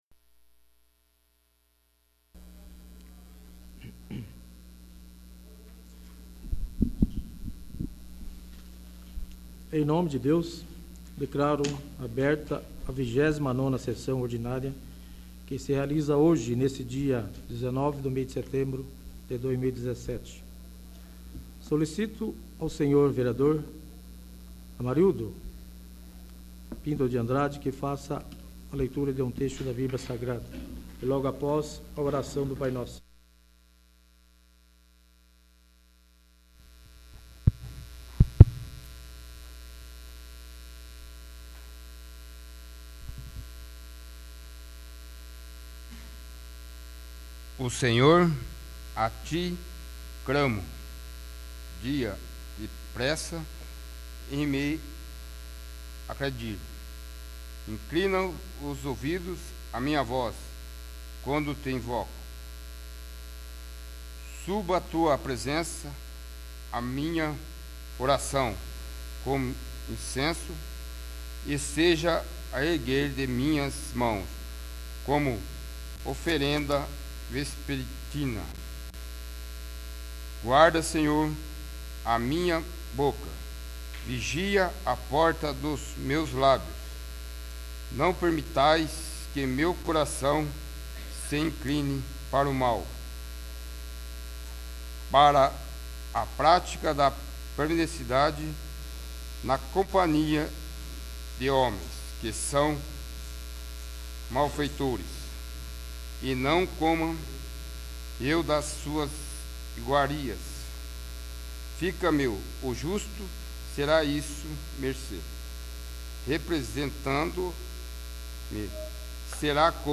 29º. Sessão Ordinária